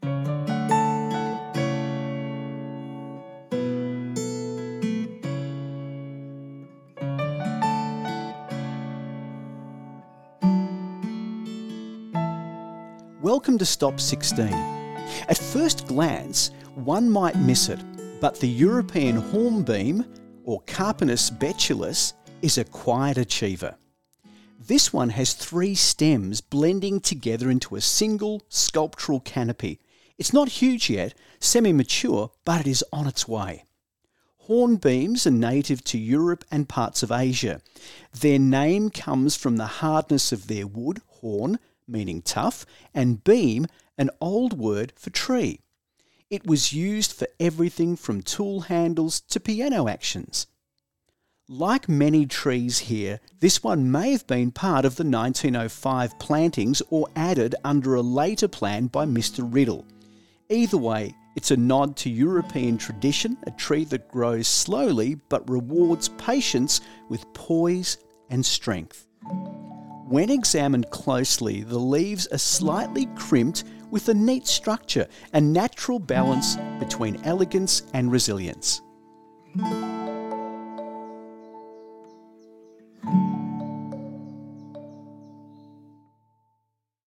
Audio Tour of the Ballan Historic Tree Walk